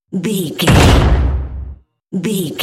Cinematic drum hit trailer
Sound Effects
Atonal
heavy
intense
dark
aggressive